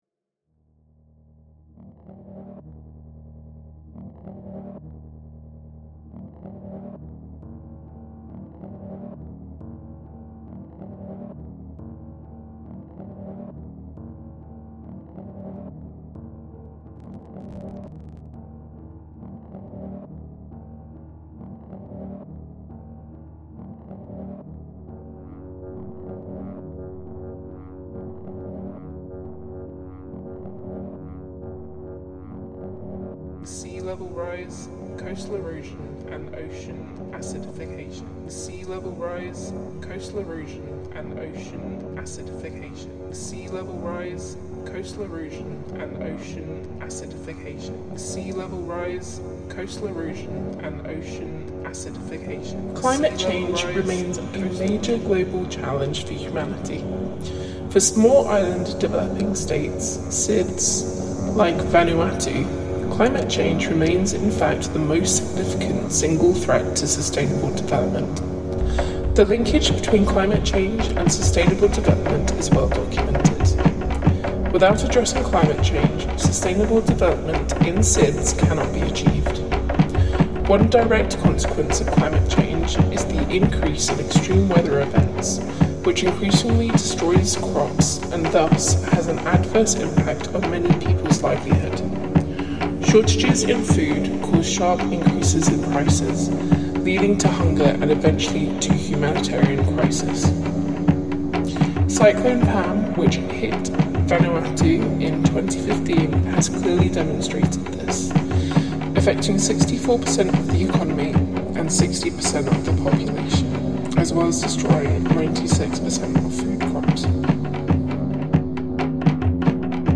other electronic samples and guitar
Drumming at Wor Tamat dancing ground reimagined